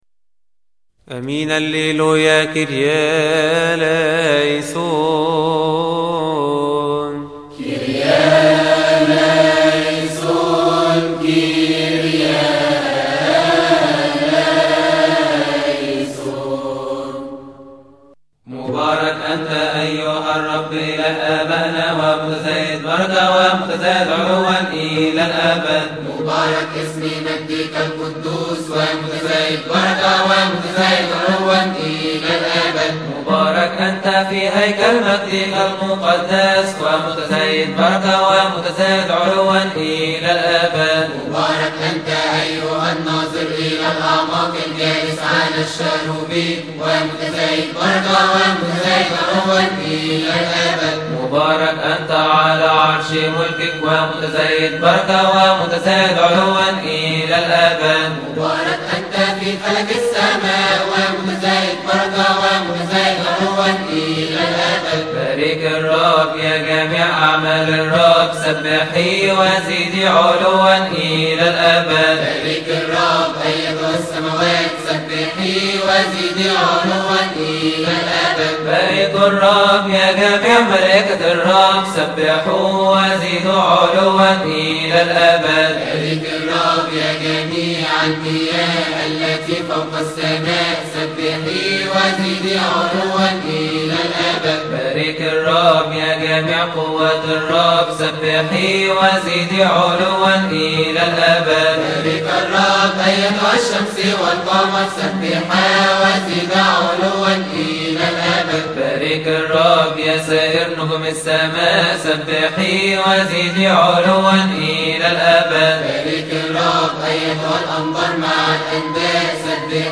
استماع وتحميل لحن الهوس الثالث عربى من مناسبة keahk